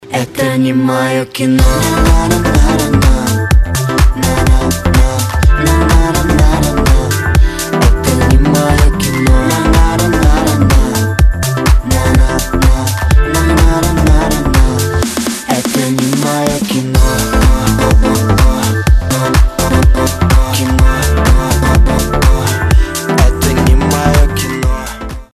• Качество: 256, Stereo
поп
dance
club